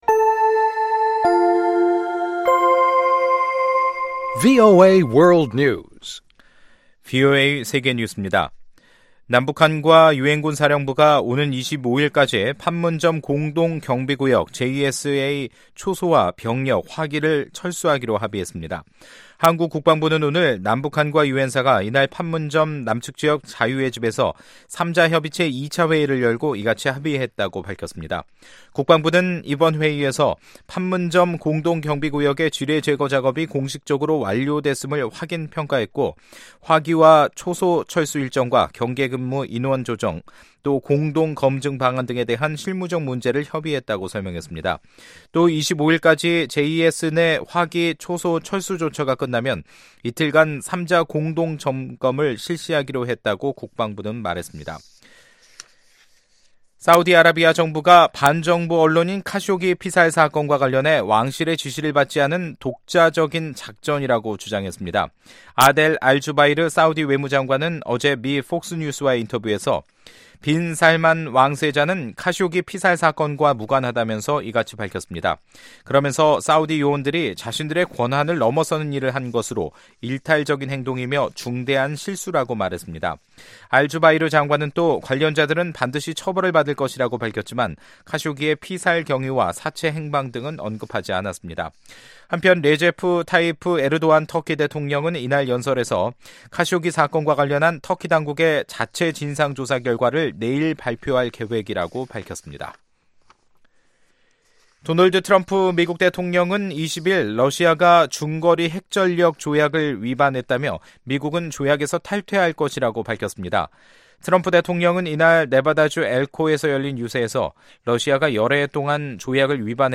VOA 한국어 간판 뉴스 프로그램 '뉴스 투데이', 2018년 10월 22일 2부 방송입니다. 도널드 트럼프 미국 대통령은 북한 문제 해결에 서두르지 말고 여유를 가지라며 낙관을 표시하고 있습니다. 미국과 한국은 오는 12월로 예정된 미-한 연합공중훈련을 유예했습니다.